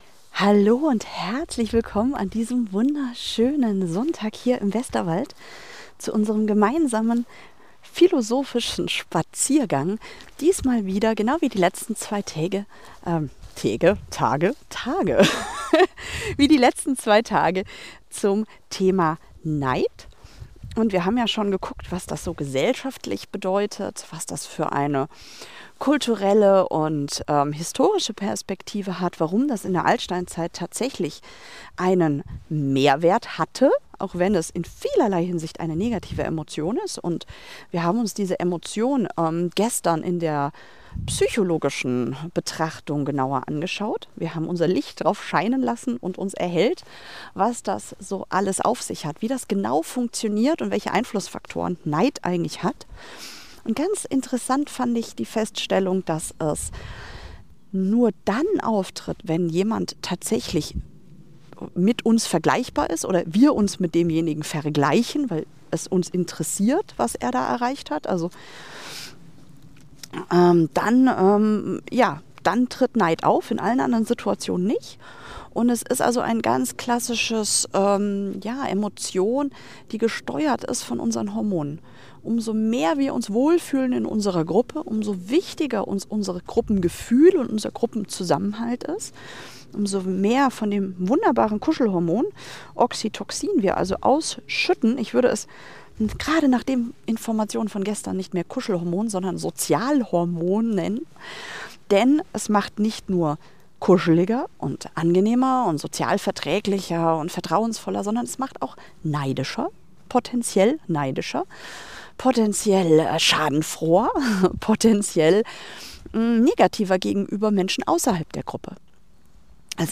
Keine theoretische Philosophie, sondern praktische Weisheit für deinen Alltag – beim Spaziergang durch den winterlichen Westerwald.